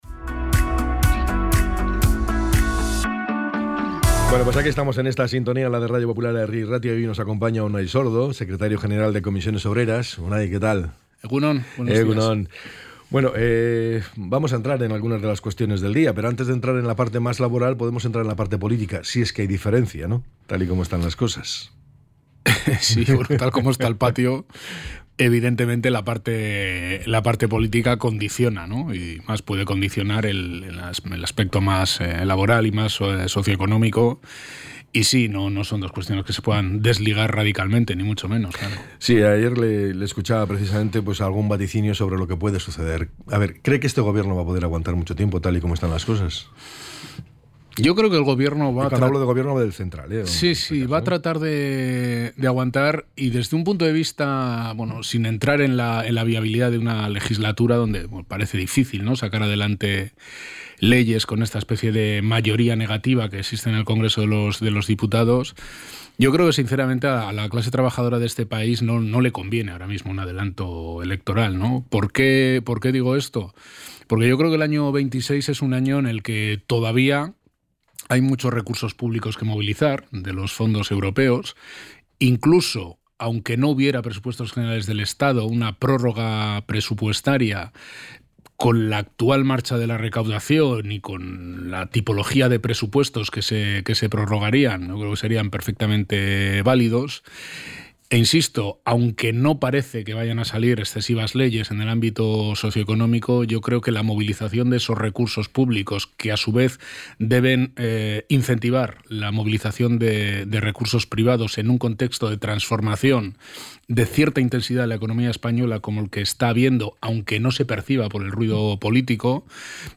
ENTREV.-UNAI-SORDO.mp3